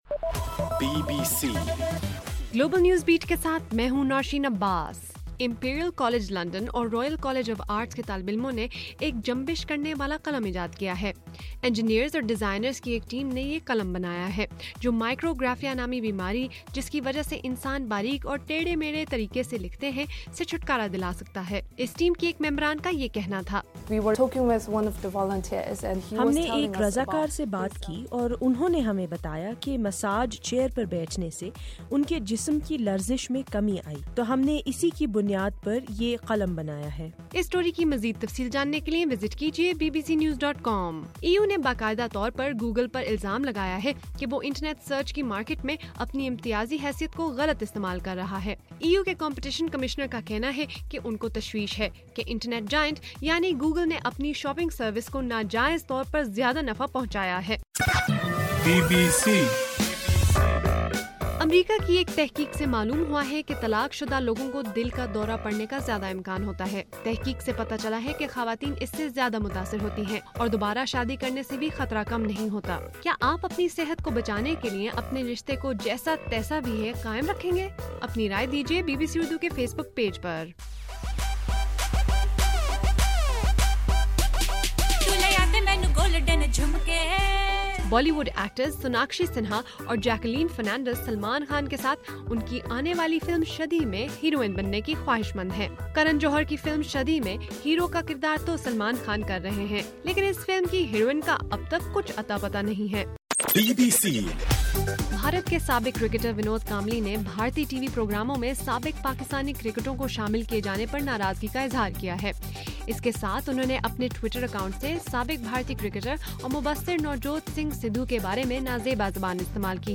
اپریل 15: رات 10 بجے کا گلوبل نیوز بیٹ بُلیٹن